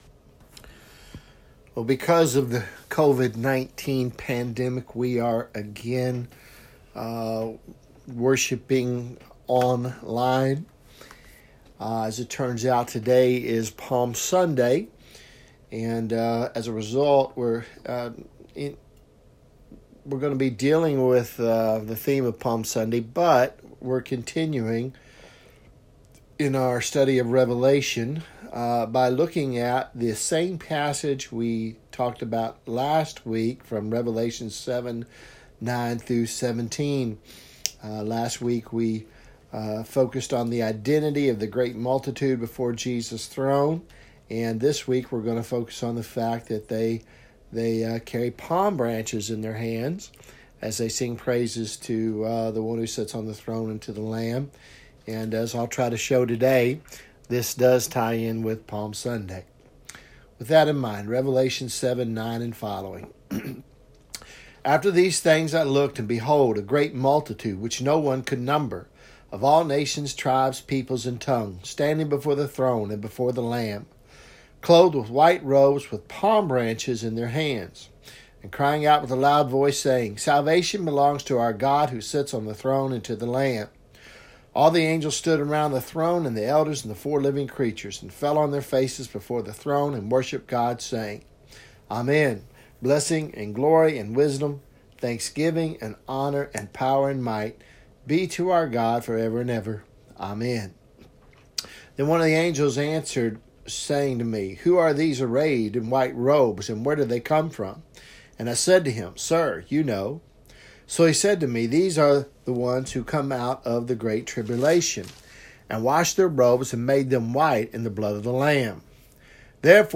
Revelation sermon series , Sermons